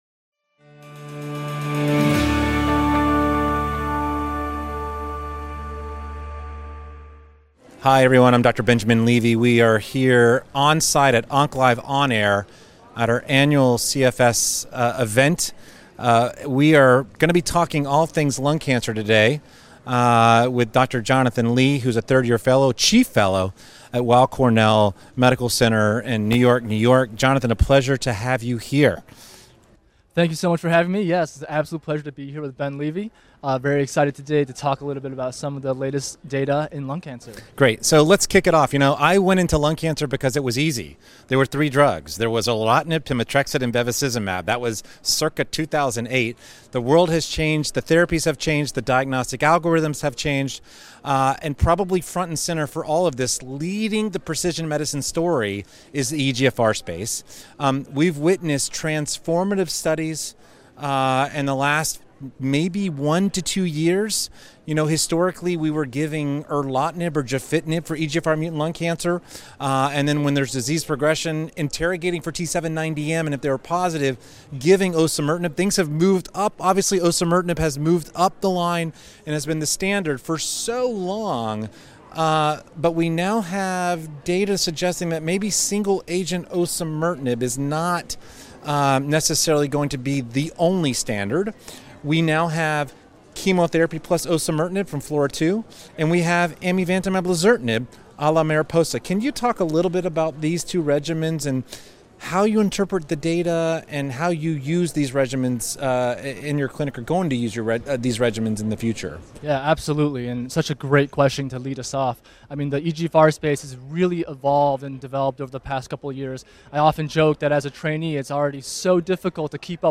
filmed live at the 43rd Annual Chemotherapy Foundation Symposium
in-depth discussion